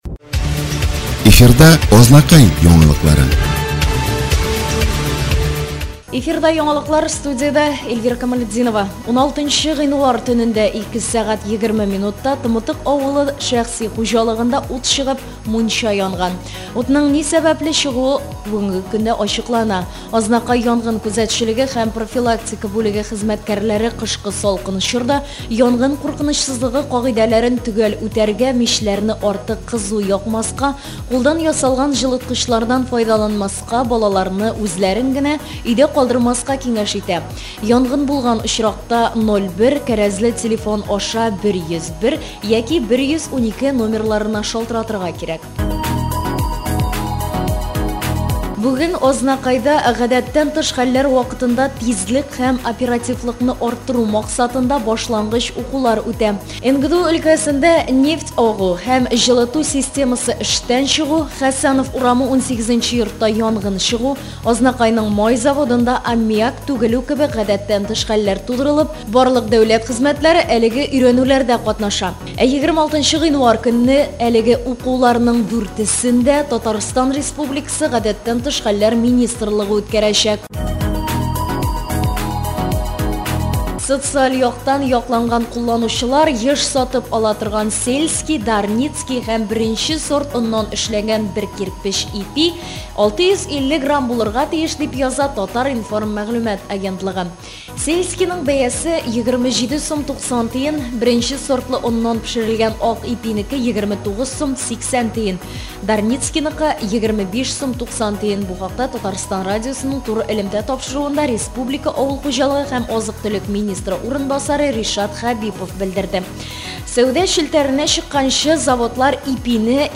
Слушать новости Азнакаевского района от 17 января